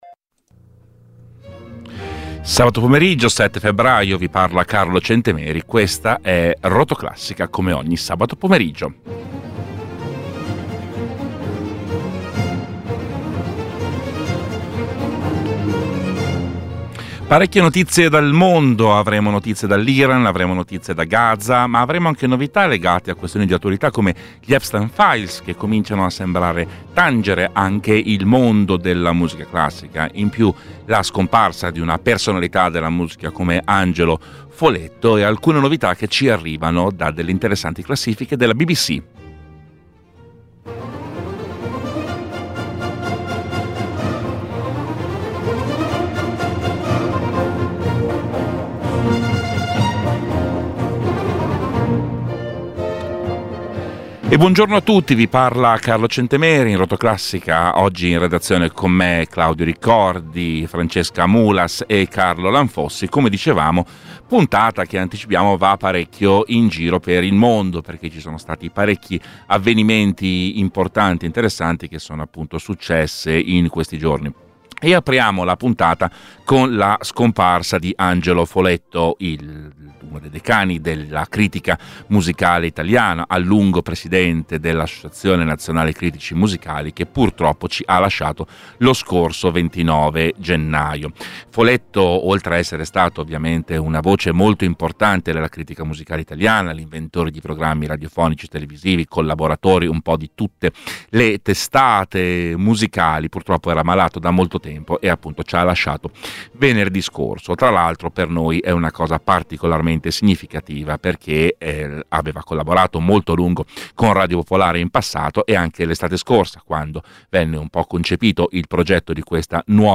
con un'intervista